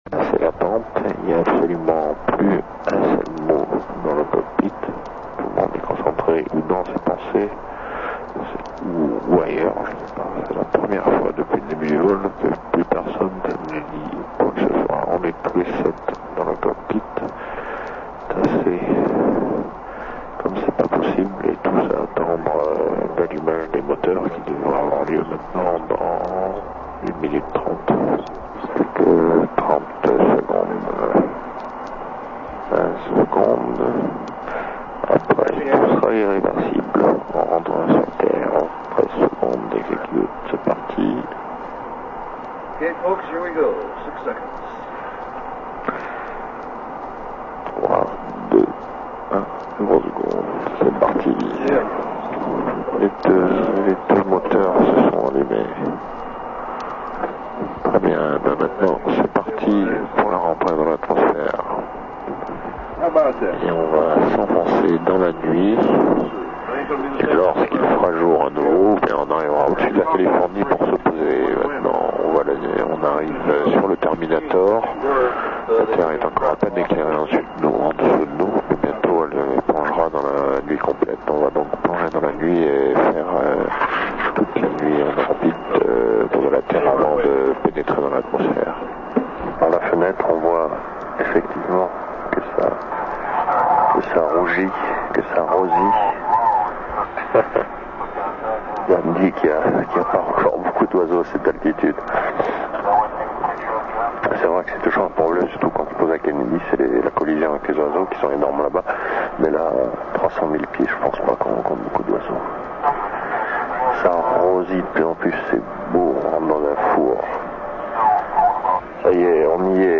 La durée totale du disque étant d' une heure environ (soit 50 Mo en MP3), je l' ai divisé en 14 partie représentant les différentes phases de la mission commentées par l' astronaute lui même.